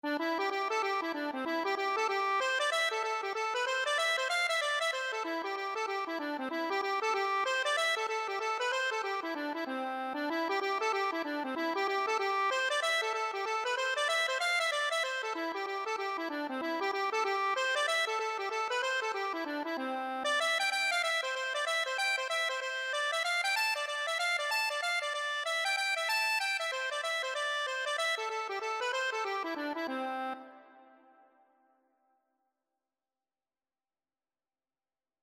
Free Sheet music for Accordion
4/4 (View more 4/4 Music)
C major (Sounding Pitch) (View more C major Music for Accordion )
Accordion  (View more Intermediate Accordion Music)
Traditional (View more Traditional Accordion Music)
Irish